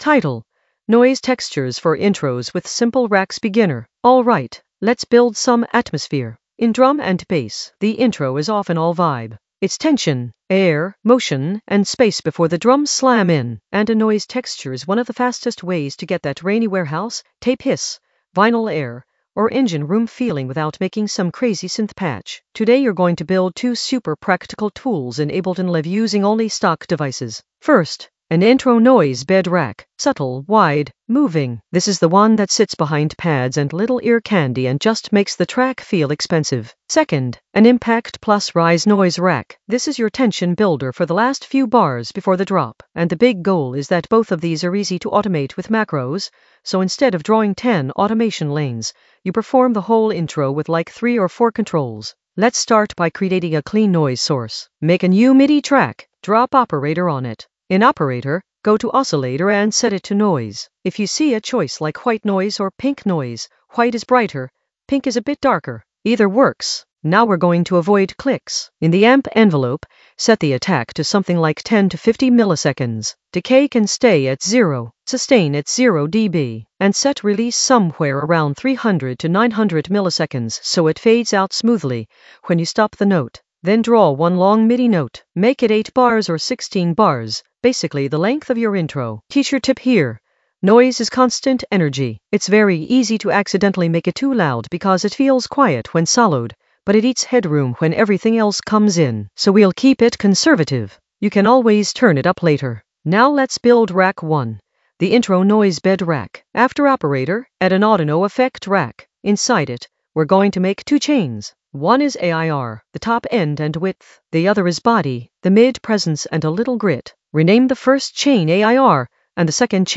An AI-generated beginner Ableton lesson focused on Noise textures for intros with simple racks in the Sound Design area of drum and bass production.
Narrated lesson audio
The voice track includes the tutorial plus extra teacher commentary.